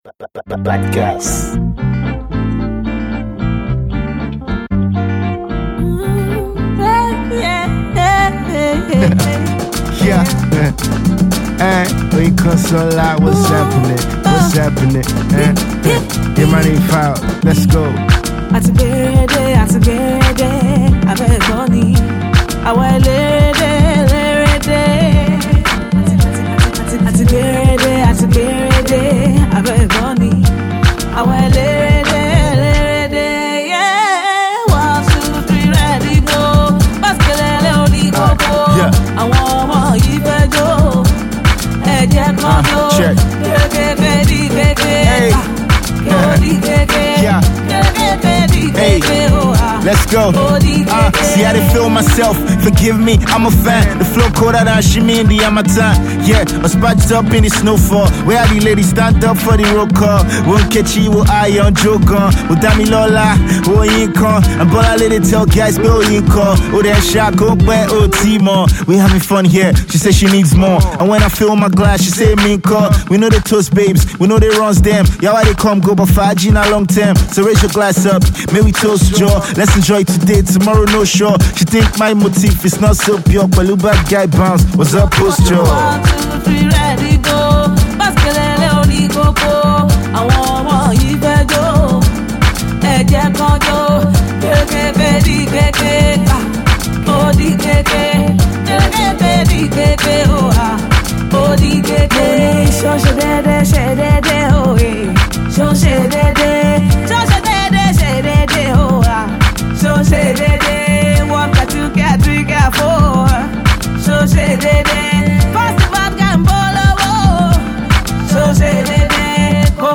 unique soul vocals
afrobeat-tinted track